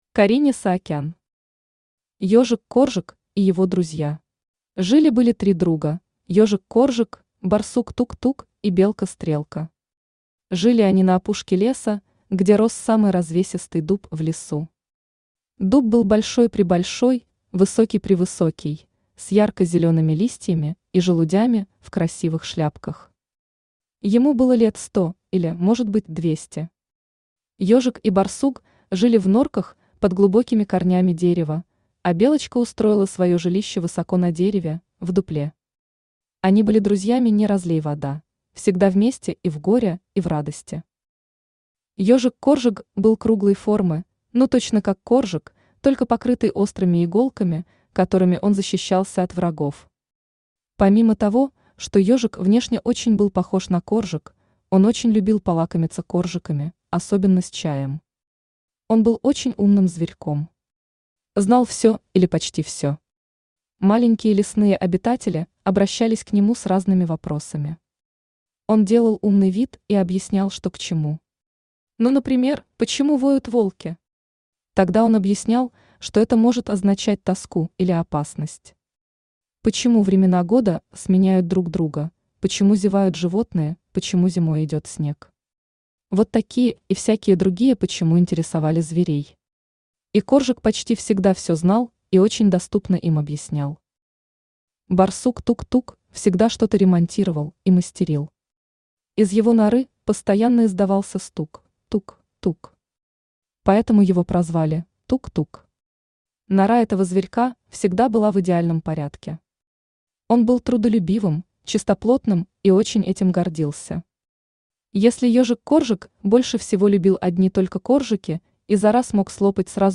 Аудиокнига Ёжик Коржик и его друзья | Библиотека аудиокниг
Aудиокнига Ёжик Коржик и его друзья Автор Карине Саакян Читает аудиокнигу Авточтец ЛитРес.